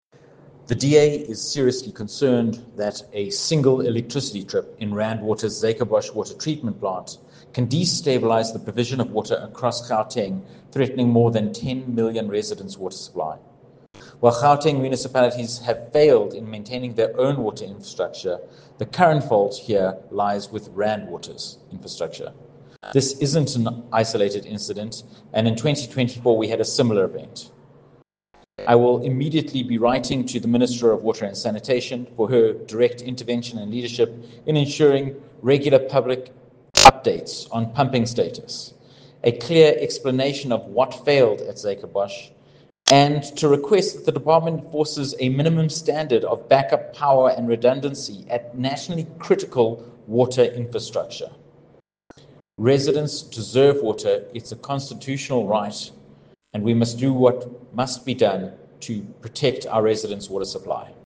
attached audio clip from Stephen Moore MP, DA Spokesperson on Water and Sanitation.